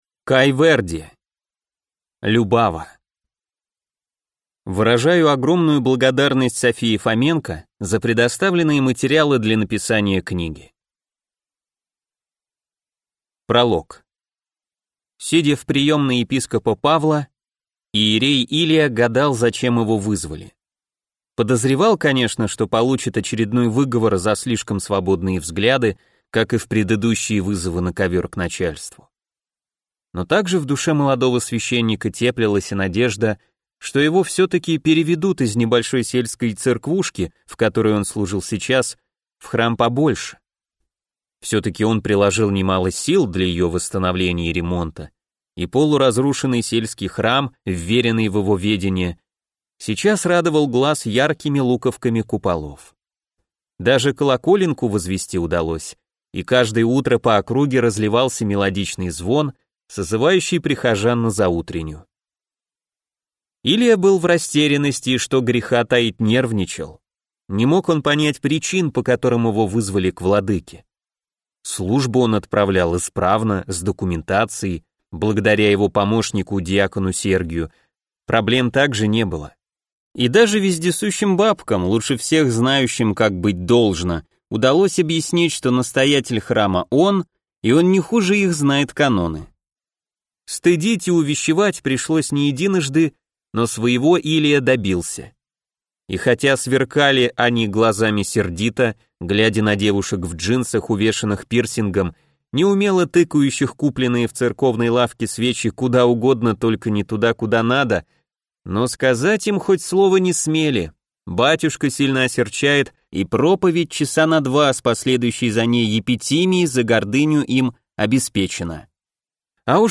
Аудиокнига Любава | Библиотека аудиокниг
Прослушать и бесплатно скачать фрагмент аудиокниги